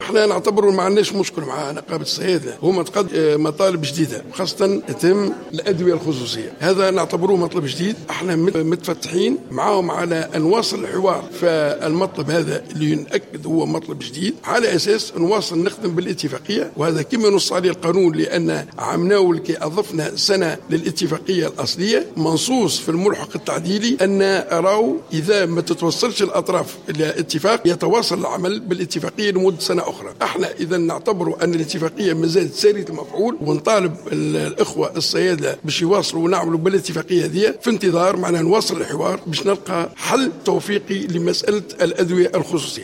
أكد وزير الشؤون الاجتماعية أحمد عمار الينباعي في تصريح لمراسل جوهرة "اف ام" على هامش اشرافه على على ختان عدد من أطفال العائلات المعوزة أن الوزارة منفتحة على الحوار والتفاوض مع نقابة الصيادلة.